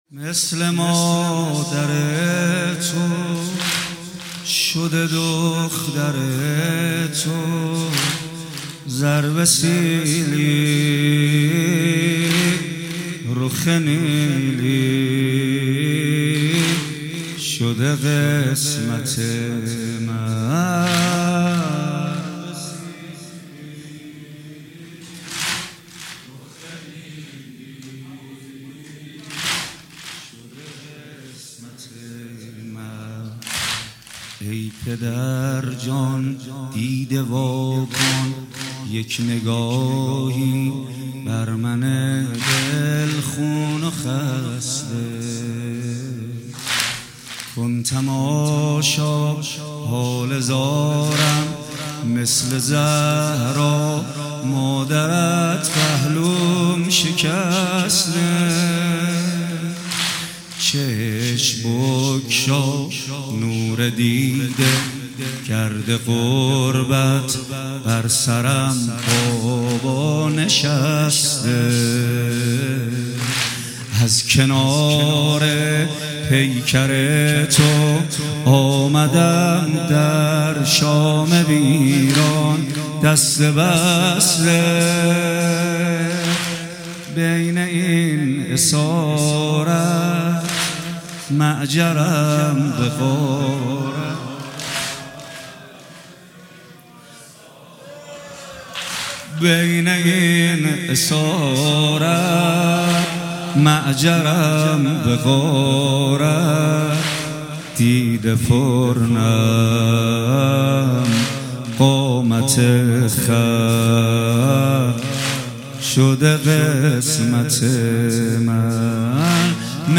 مداحی شب 3 سوم محرم 1402 محمد حسین پویانفر